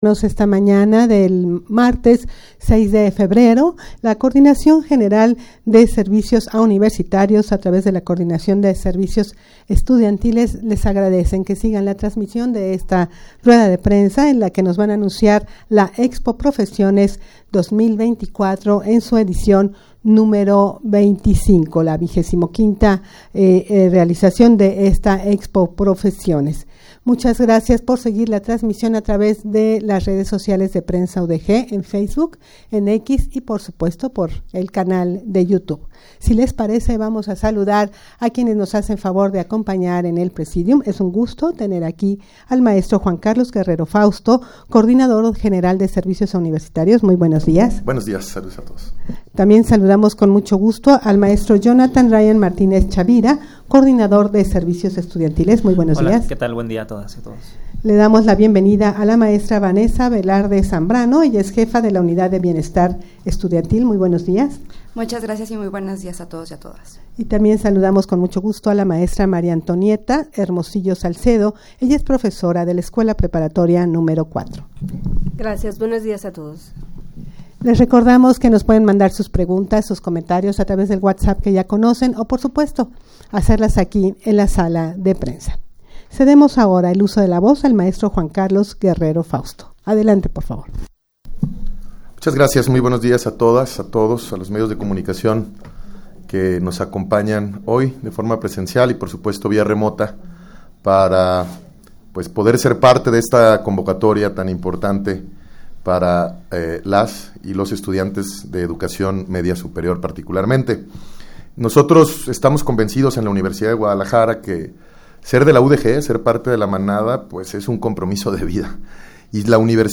Audio de la Rueda de Prensa
rueda-de-prensa-para-anunciar-expo-profesiones-2024-edicion-xxv.mp3